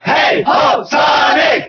File:Sonic Cheer German SSBB.ogg
Sonic_Cheer_German_SSBB.ogg.mp3